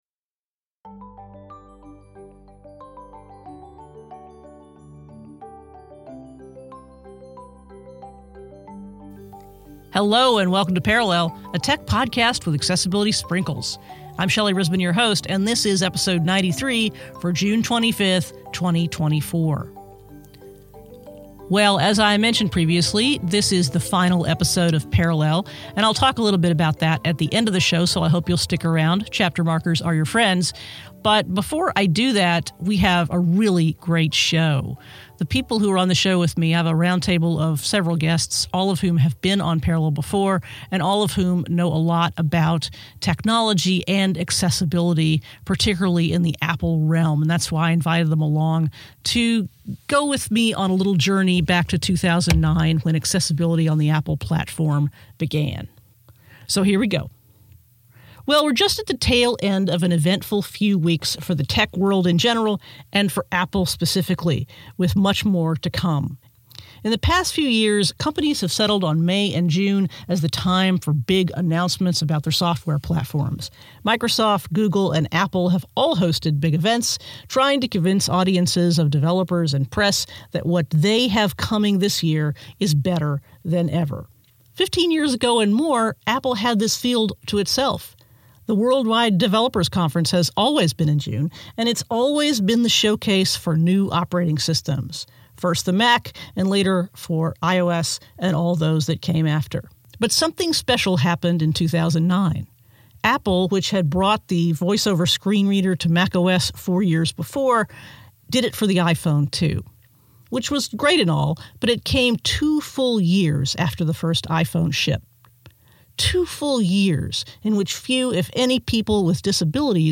Today, we offer an all-star panel of podcasters and tech pundits with a lot to say about the first 15 years of mobile accessibility from Apple. To my delight, the show veered hard into present and future topics.